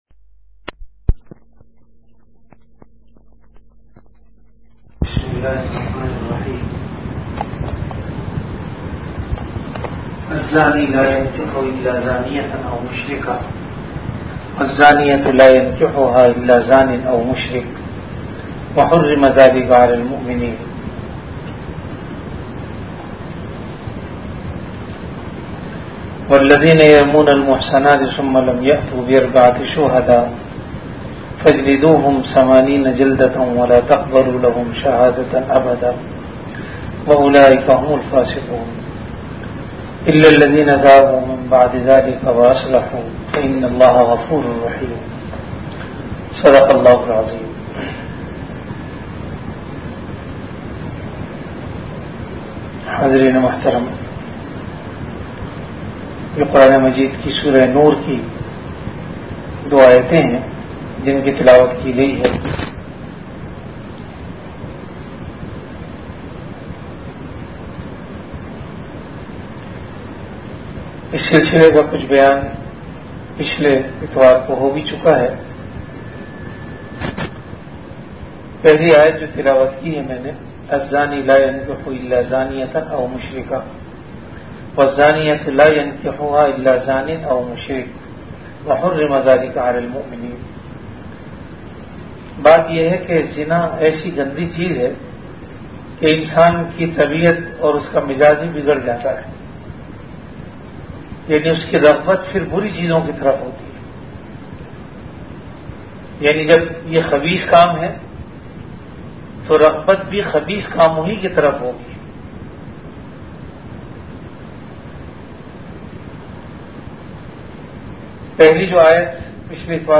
After Isha Prayer